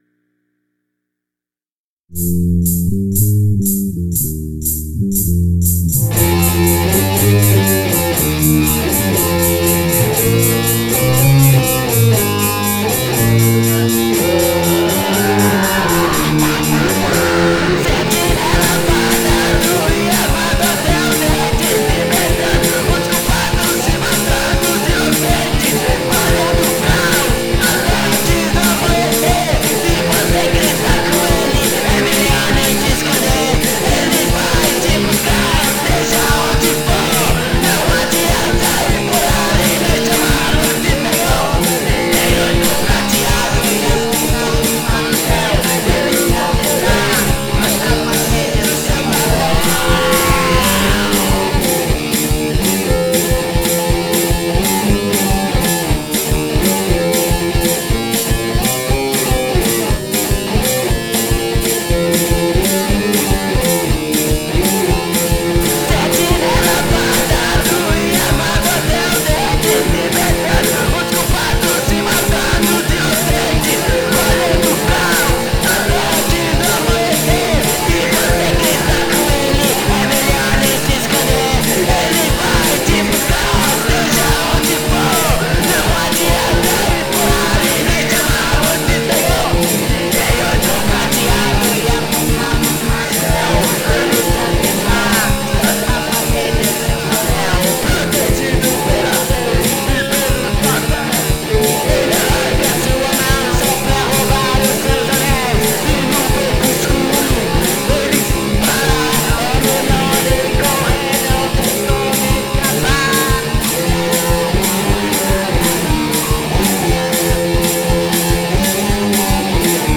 EstiloPunk Rock